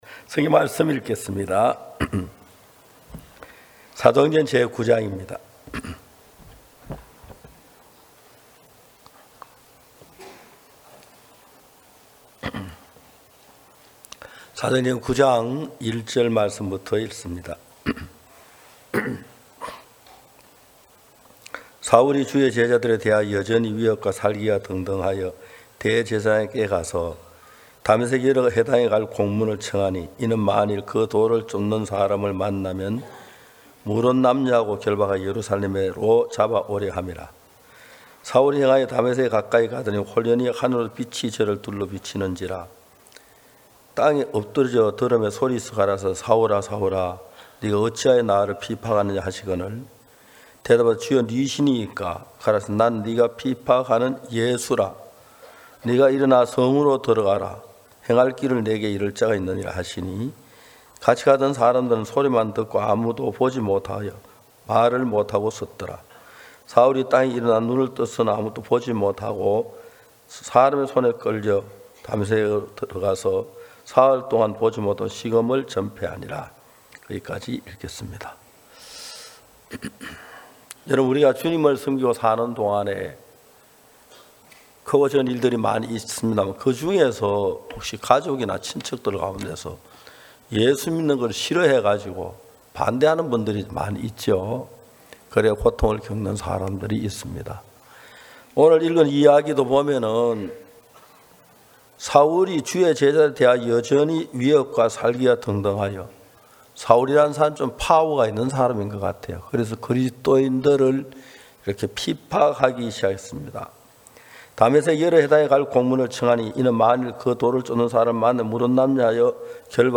성도들이 모두 교회에 모여 말씀을 듣는 주일 예배의 설교는, 한 주간 우리 마음을 채웠던 생각을 내려두고 하나님의 말씀으로 가득 채우는 시간입니다.